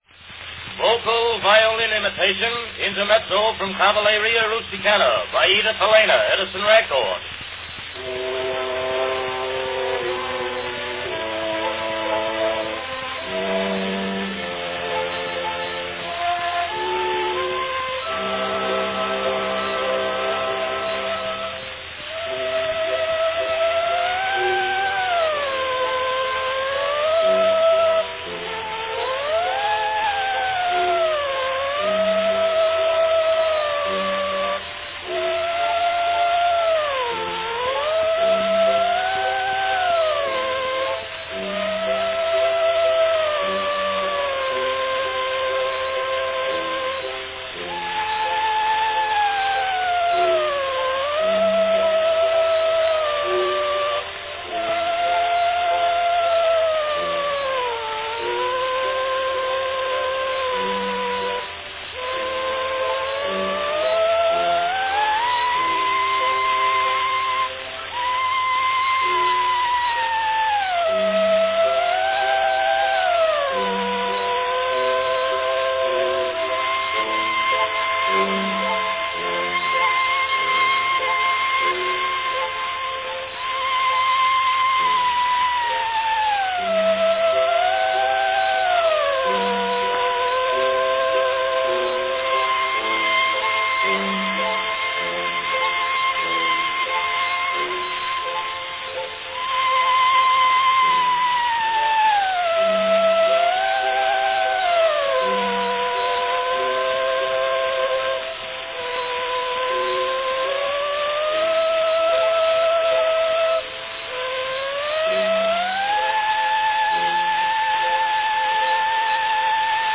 One of the more unusual 2-minute wax cylinder recordings made by Edison
Category Vocal violin imitation
Coloratura soprano
She would appear to play the Intermezzo from Cavalleria Rusticana on a violin; she was actually singing the violin part.
In our Record the final notes are sung just to convince the listeners that the violin is being imitated.